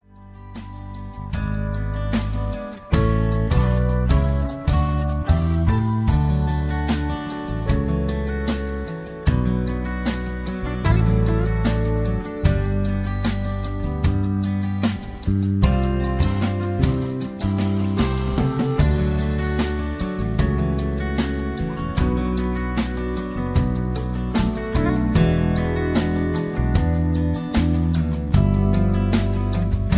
karaoke, mp3 backing tracks
easy litstening, rock and roll, rock, backing tracks